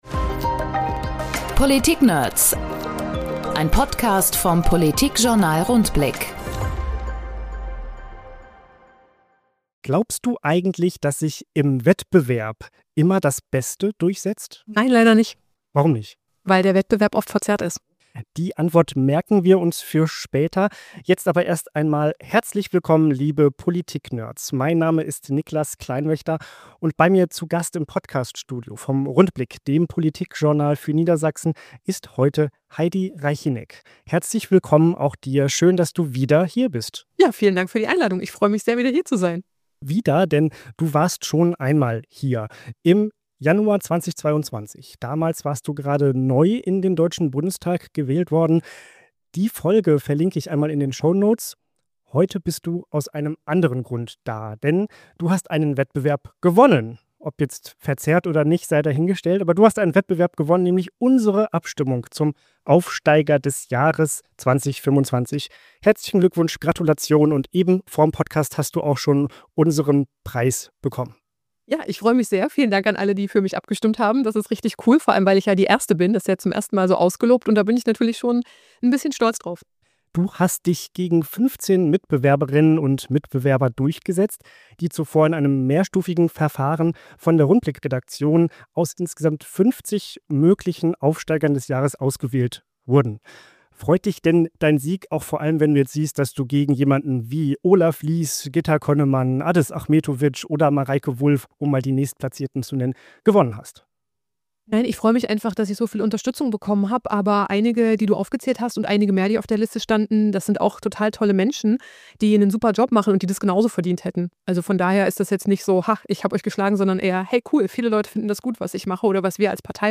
Podcast-Gespräch